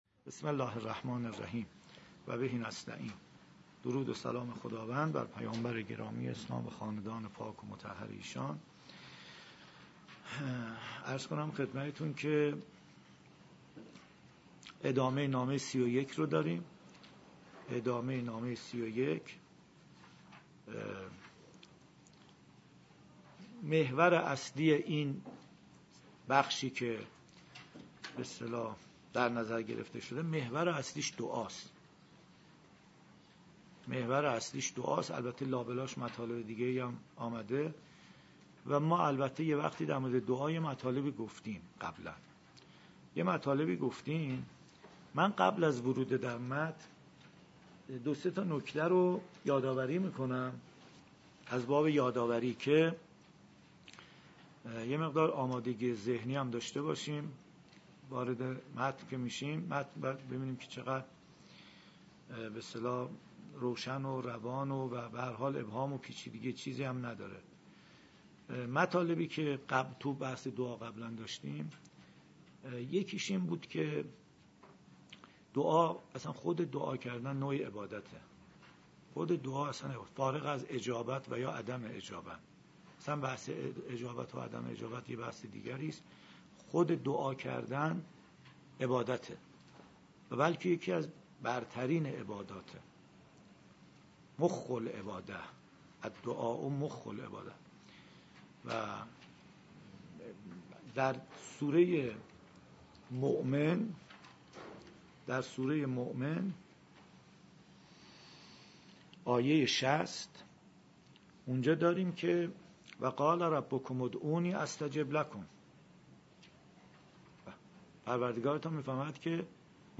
091 - تلاوت قرآن کریم